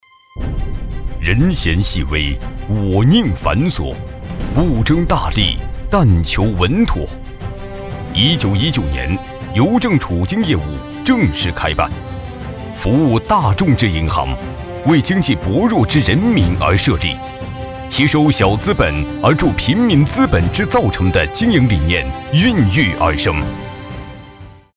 男53号配音师
近几年开始全职从事配音工作，风格比较多变。
代表作品 Nice voices 专题片 宣传片 舌尖 课件 广告 飞碟说 颁奖 专题片-男53-邮政储金.mp3 复制链接 下载 专题片-男53-党建类.mp3 复制链接 下载